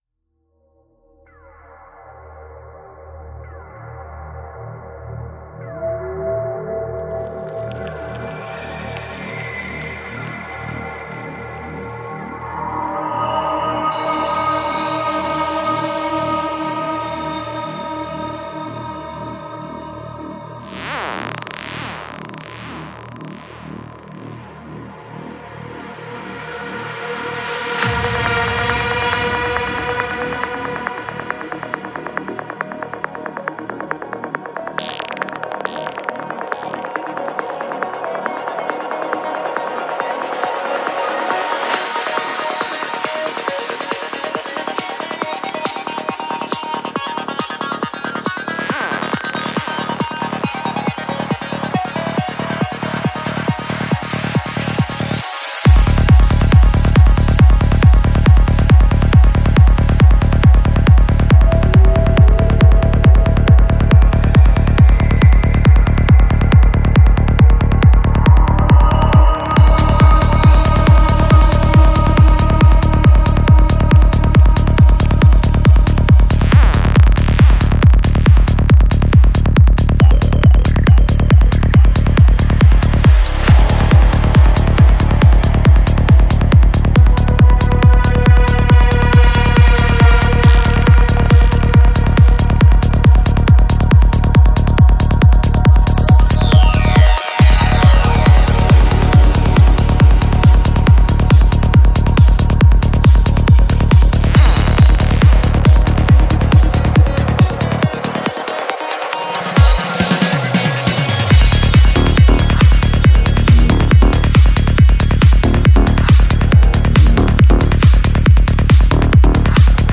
Жанр: Psytrance